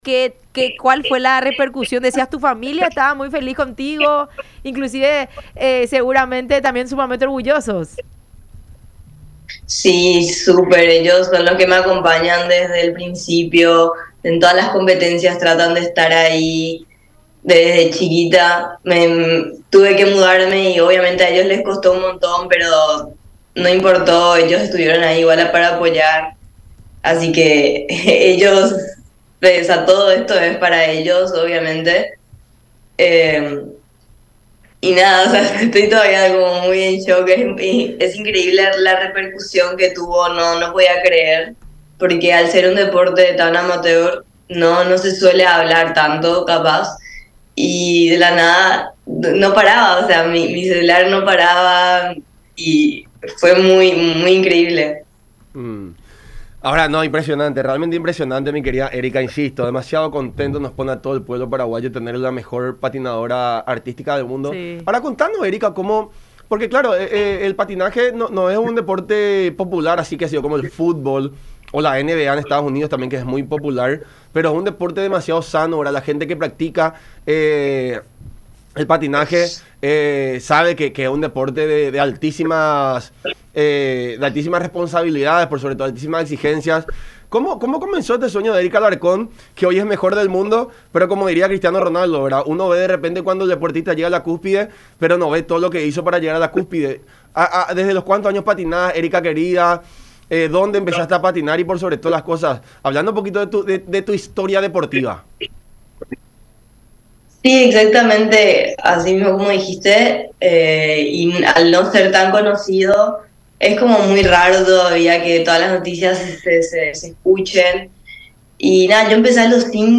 En charla con La Unión 800 AM, explicó cómo fueron cada uno de sus pasos hasta llegar a lo más alto del patinaje en el planeta.